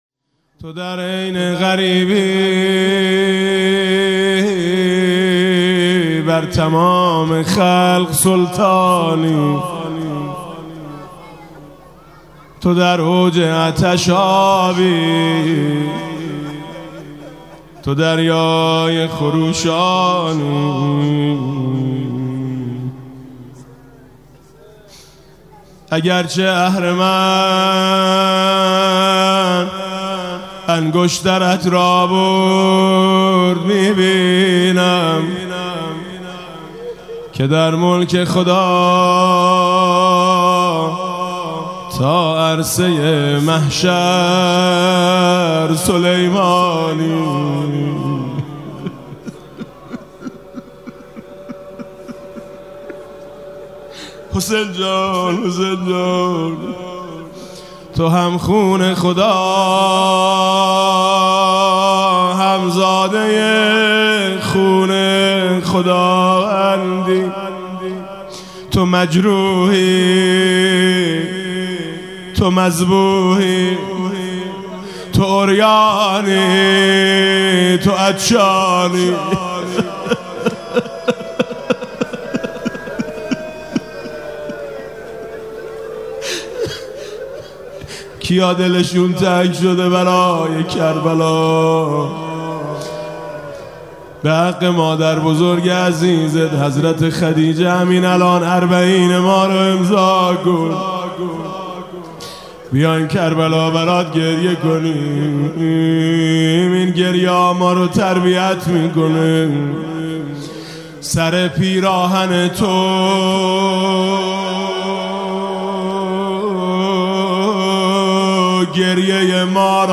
شب دهم ماه رمضان ۱۴۰۳
[حرم حضرت فاطمه معصومه (س)]
با نوای: حاج میثم مطیعی